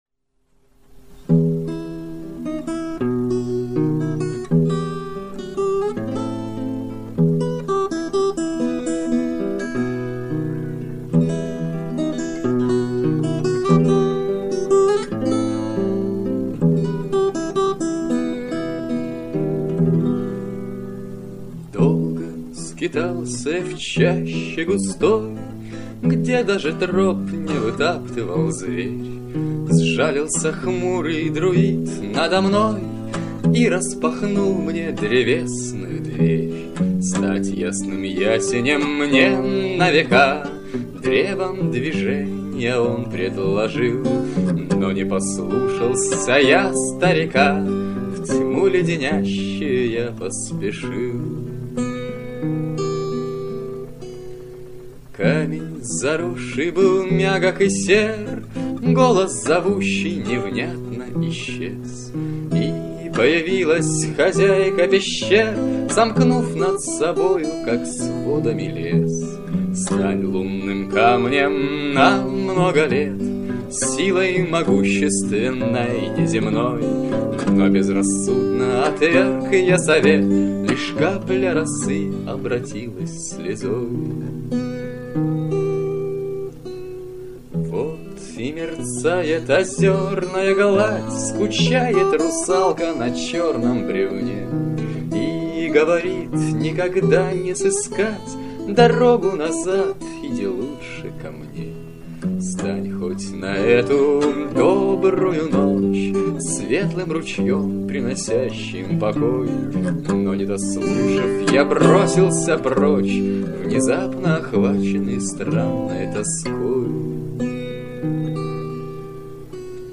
На одной старой кассете в мамином архиве нашёлся целый комплект всякого добра из 1995 года.